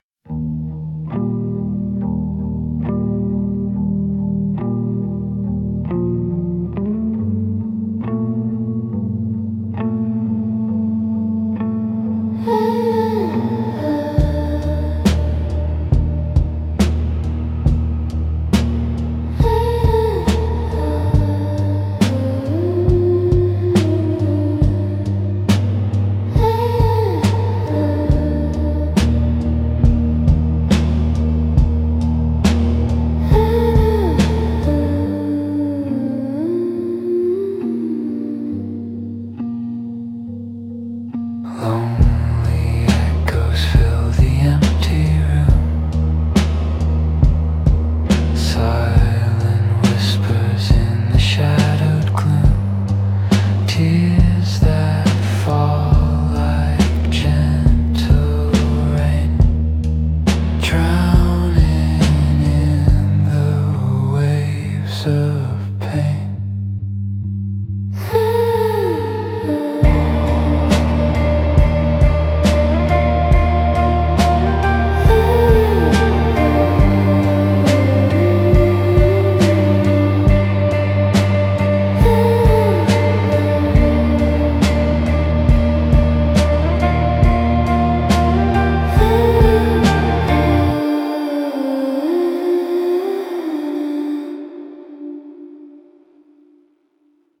lonely-echoes-fill-the-empty-room.mp3